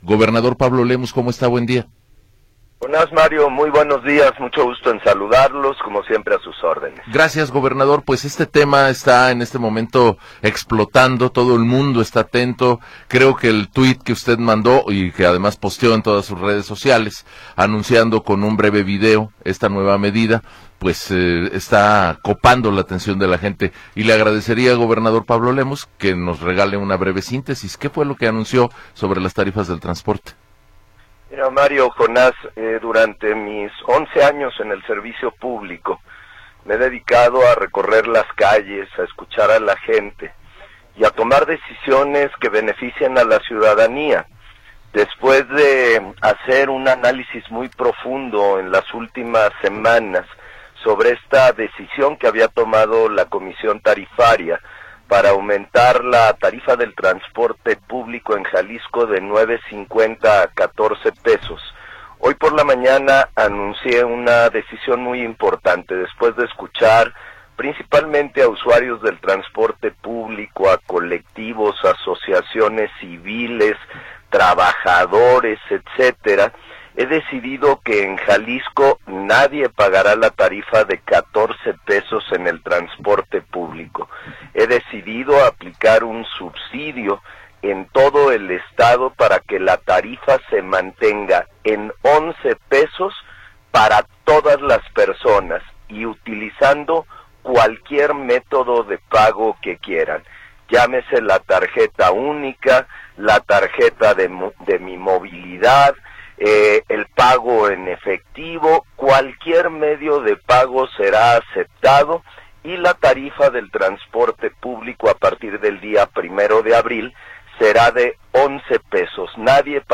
Entrevista con Pablo Lemus Navarro
El Gobernador de Jalisco, Pablo Lemus Navarro, nos habla sobre los cambios a la tarifa del transporte público.